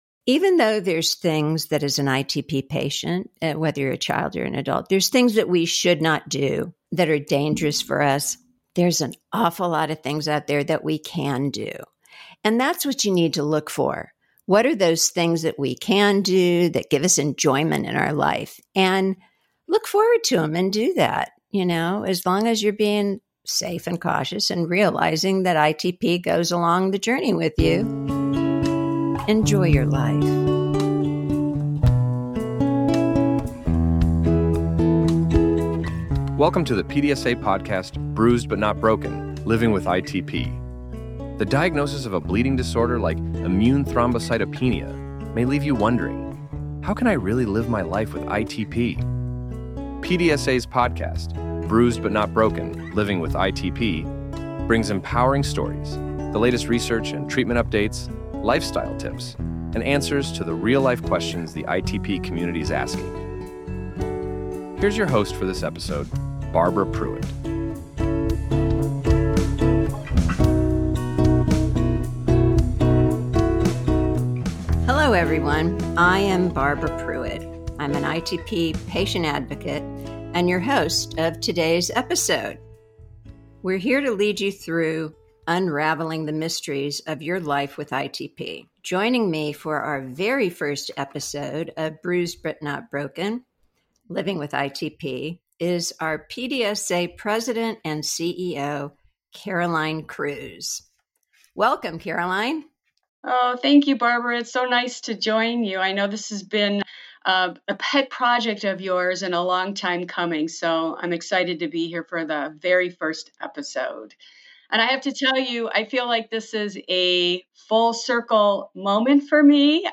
an insightful conversation